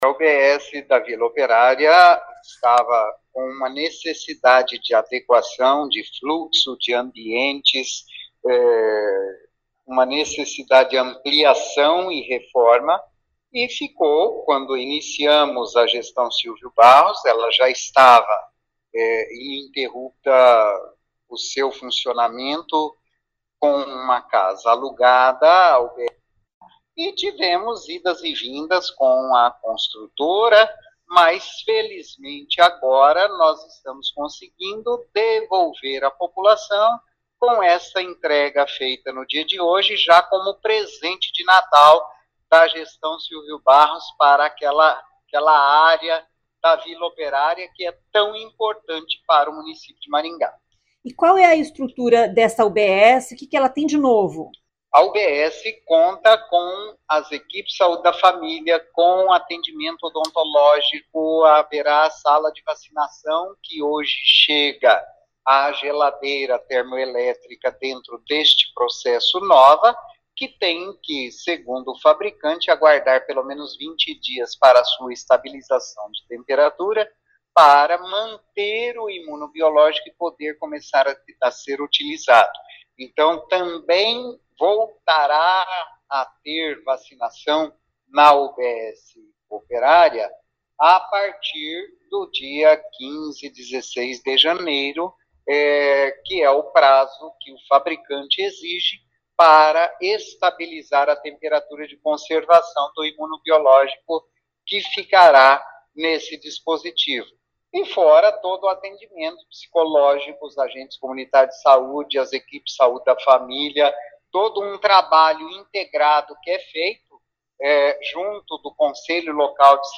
Ouça o que diz o secretário de Saúde Antônio Carlos Nardi.